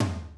Index of /90_sSampleCDs/Zero-G Groove Construction (1993)/Drum kits/New Jack Swing/Kick